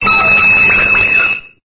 Cri de Neitram dans Pokémon Épée et Bouclier.